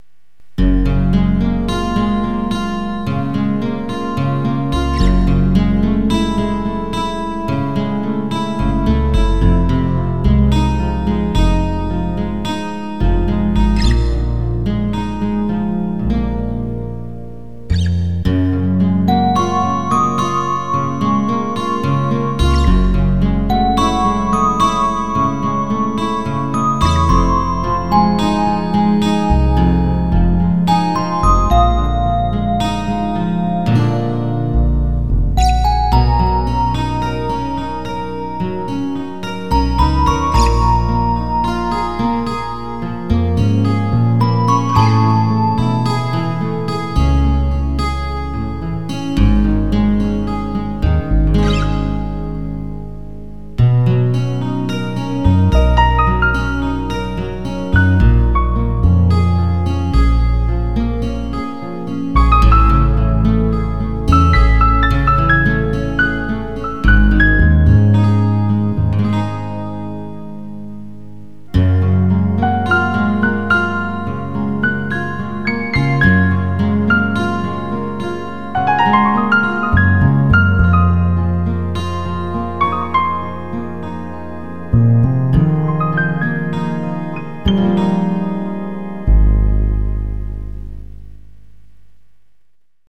印象に残らないエロげーの曲を意識しました。
ただの日常だね。